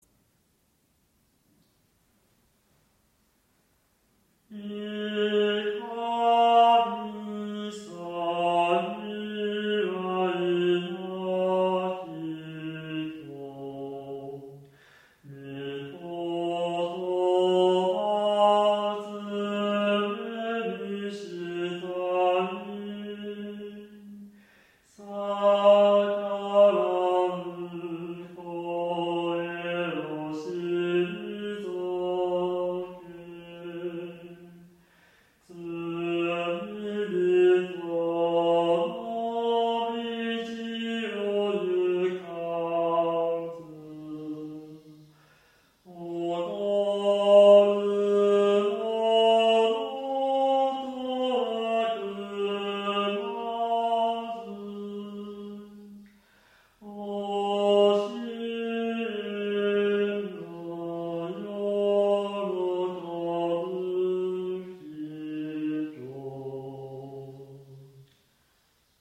２度上行は広く、３度上行は狭く取って音律を折衷的に整えています。
途中から小鳥がさえずっていますが、不思議とじゃまになりません。